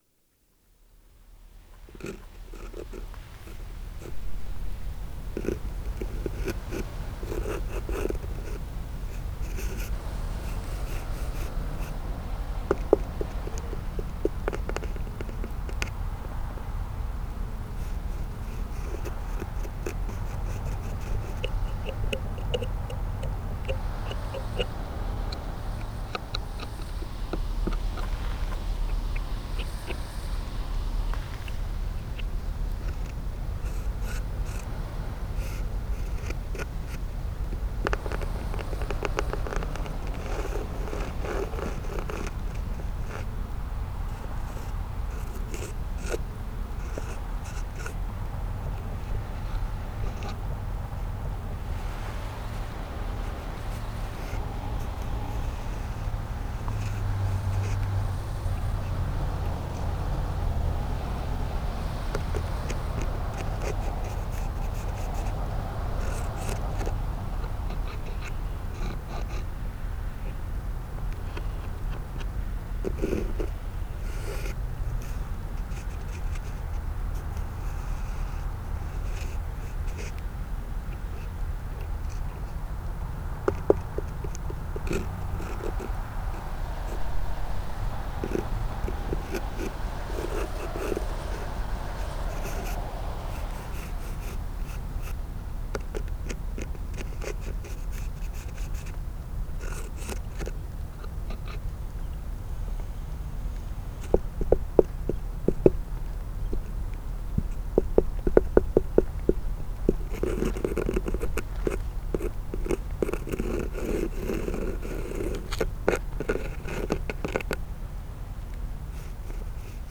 sound art
Contact microphone performance mixed with ambience from the Lower Lonsdale Community Garden. Scratching, plucking, resonating through the bark.
Nature sounds